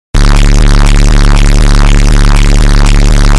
Sound Effects
Bass Boost